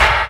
SNR CLOG 0FR.wav